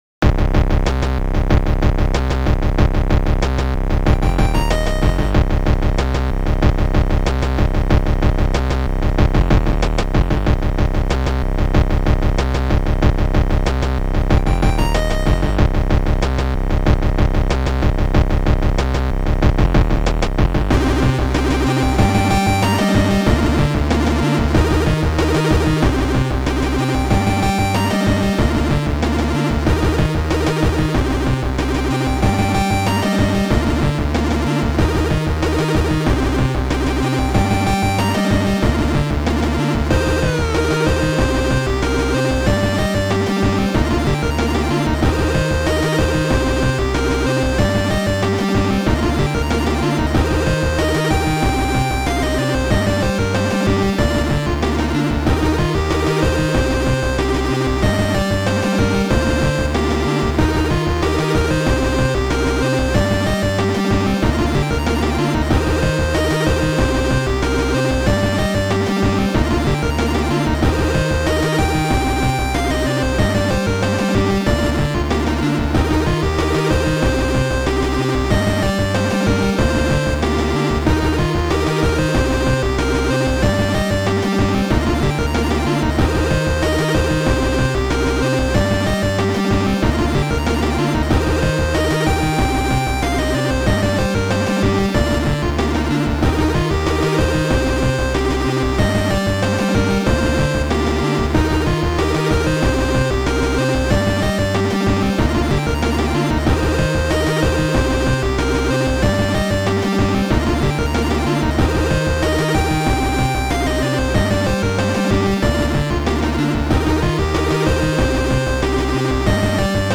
chiptunes